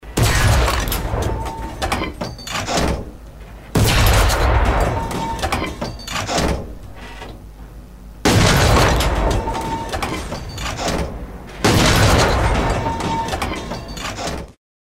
Звук выстрела орудия 45мм СССР в World of Tanks